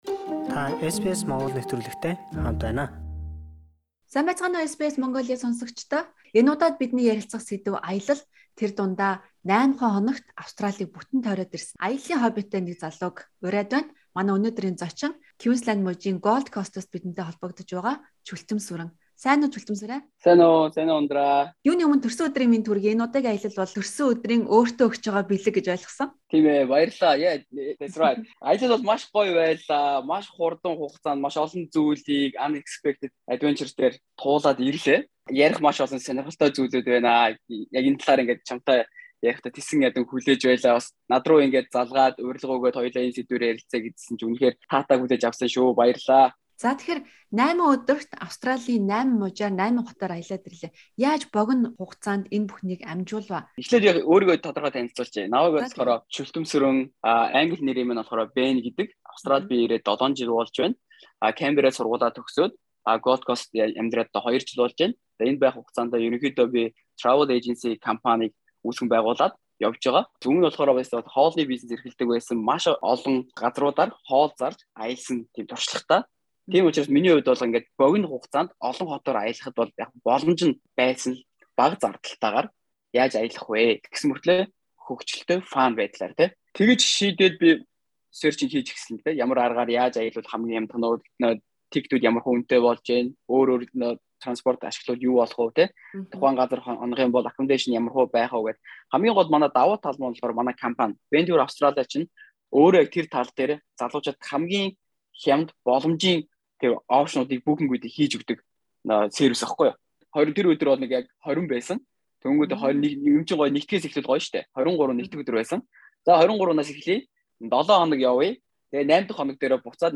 Түүнтэй хийсэн ярилцлага танд мэдлэг, ирээдүйд хийх аялалын тань хөтөч болно гэдэгт итгэлтэй байна.